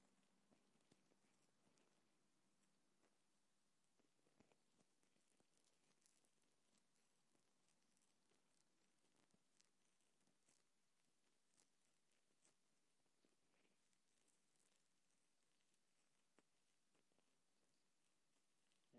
施工现场
描述：施工现场机器和卡车的环境噪声
标签： 环境 现场记录 施工
声道立体声